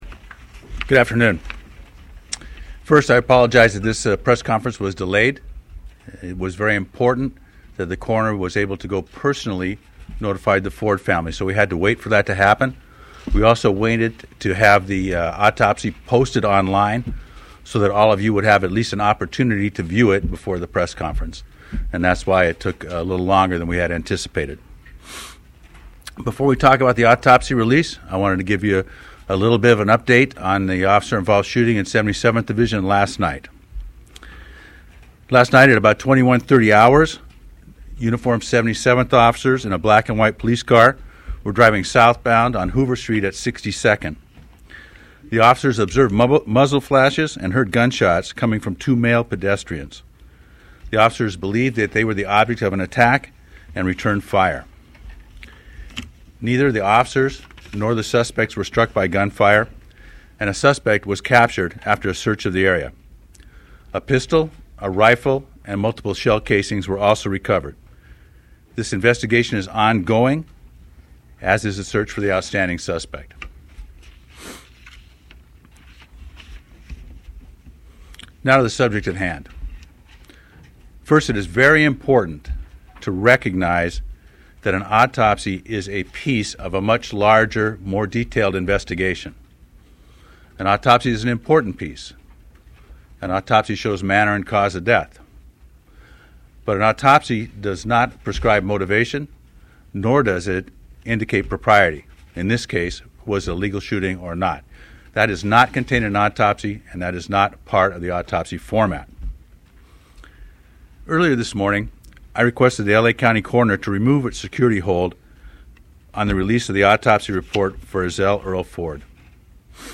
Chief of Police podcast regarding autopsy report